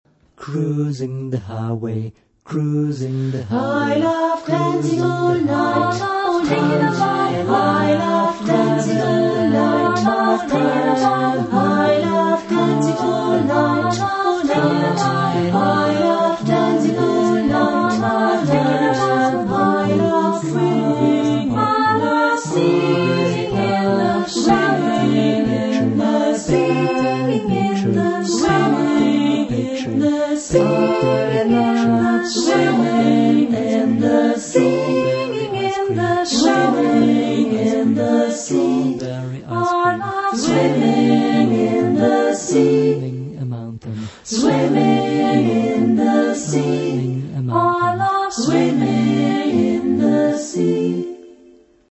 Genre-Style-Forme : Profane ; Jazz vocal ; Close Harmony
Caractère de la pièce : jazzy ; joyeux ; rythmé
Type de choeur : SATB  (4 voix mixtes )
Tonalité : accords de jazz